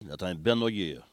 Patois - archive
Catégorie Locution